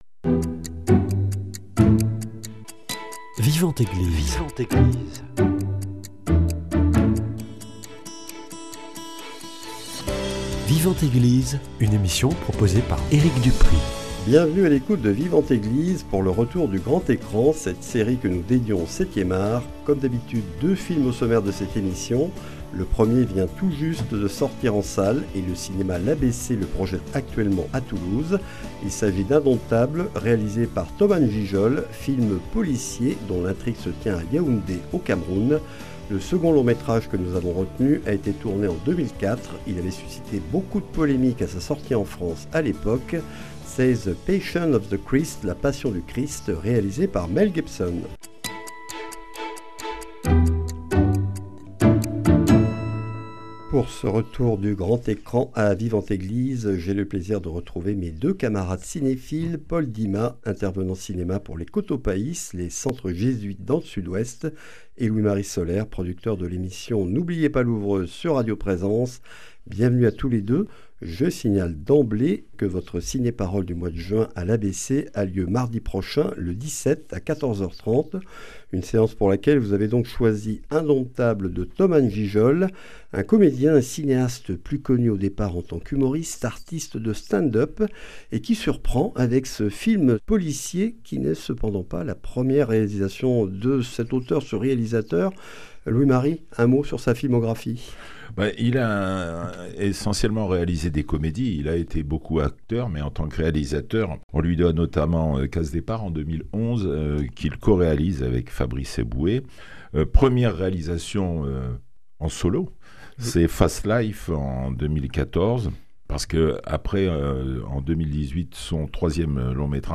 Au sommaire de ce numéro, une discussion autour d’ Indomptables , long-métrage de Thomas Ngijol, actuellement à l’affiche du cinéma l’ABC à Toulouse, puis un retour sur La Passion du Christ , film de Mel Gibson